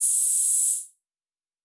Air Hiss 3_05.wav